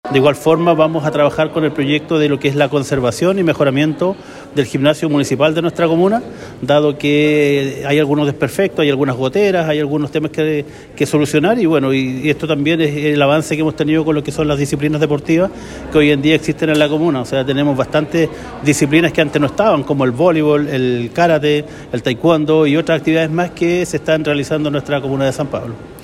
Además con el objetivo de continuar incentivando la práctica deportiva en la comuna de San Pablo, el Alcalde Juan Carlos Soto señaló que se trabajará en un proyecto de mejoramiento y conservación del Gimnasio Municipal.